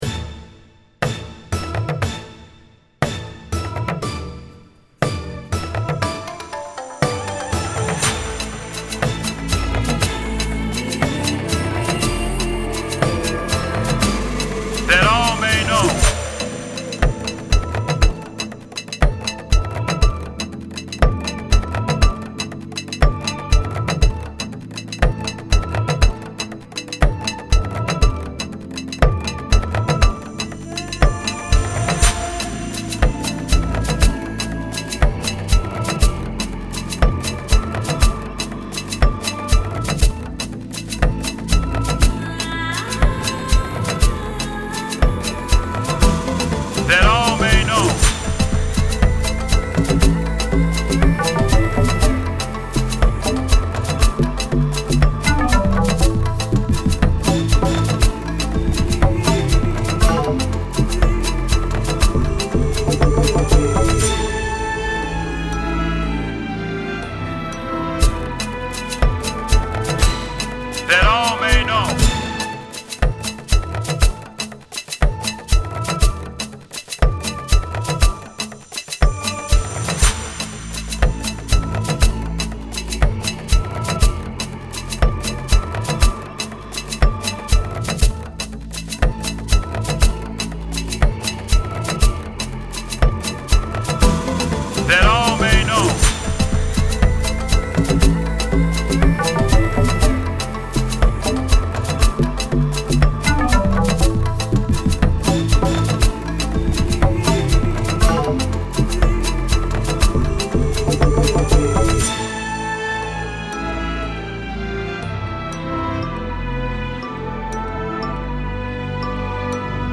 Category: Afro Apala